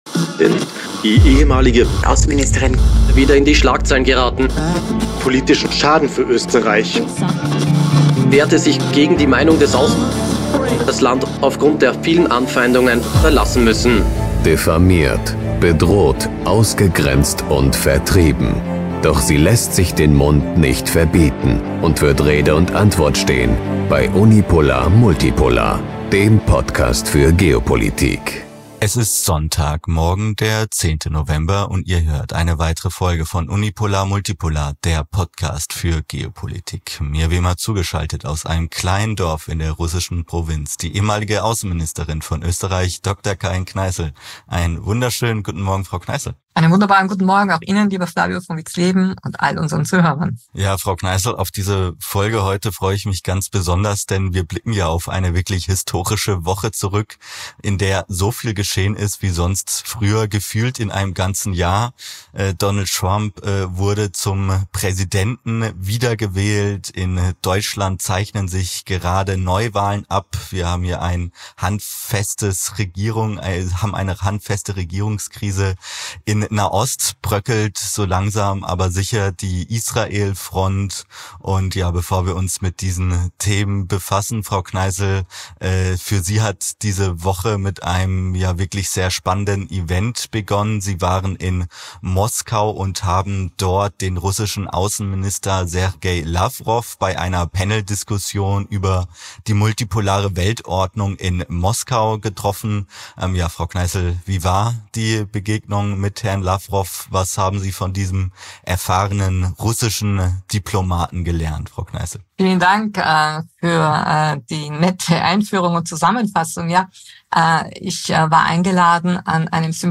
Dabei diskutieren die beiden den zu Ende gegangenen Wahlkampf und die wichtigsten Positionen, die Trump vertreten hat, wie beispielsweise in Fragen der Migration oder der wirtschaftlichen Stärkung des Landes. Außerdem gehen sie darauf ein, was dieser Wahlsieg nun für die Konflikte im Nahen Osten und in der Ukraine bedeuten wird und wie China, der größte Konkurrent der USA, auf diese Wahl blickt. Zuletzt sprechen sie noch über den Rücktritt des israelischen Verteidigungsministers Joav Galant und das Regierungschaos in Deutschland. aktualisiert am: Sonntag, 10.